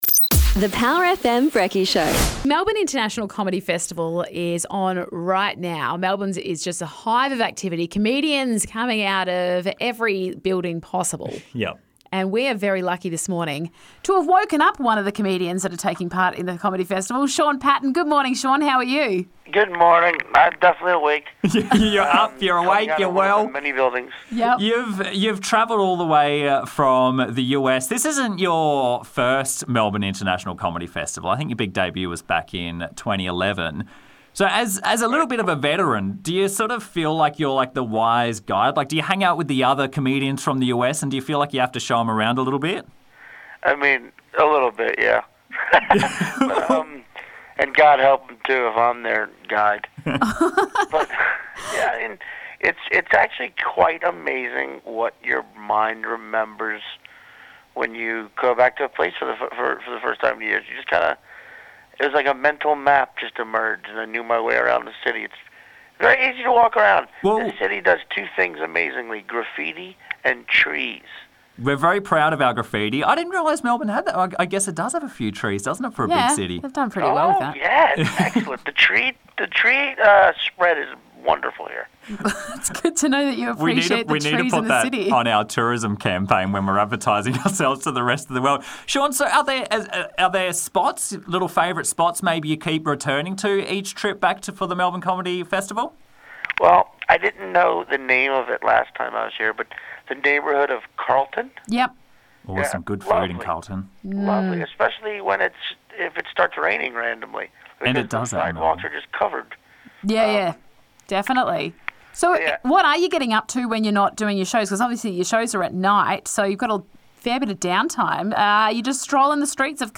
Comedian, Sean Patton, on the Power FM Brekky Show